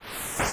steinschieben.wav